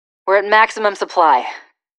Um den Spielern von Starcraft 2: Legcay of the Void bereits jetzt einen ersten Eindruck von diesem kommenden Ansager zu vermitteln, beinhaltete die Ankündigung der Entwickler dann freundlicherweise auch noch eine Vorschau auf einige der englischen Sätze dieses kommenden Produkts (Es wird eine deutsche Lokalisierung geben).